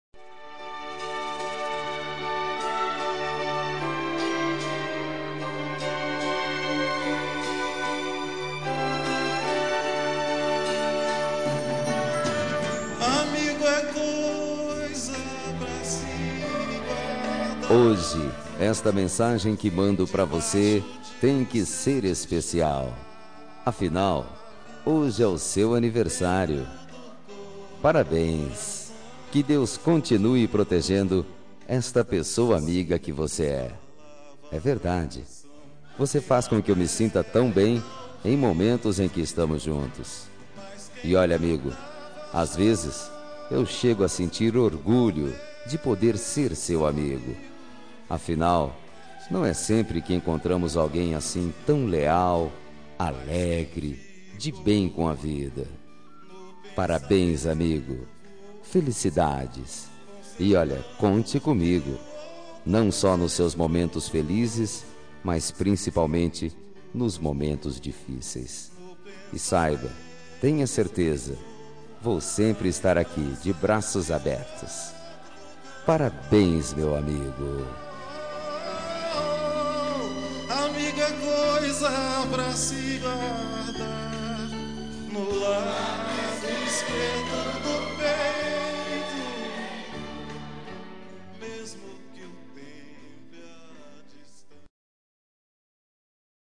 Telemensagem de Aniversário de Amigo – Voz Masculina – Cód: 1608